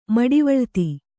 ♪ maḍivaḷti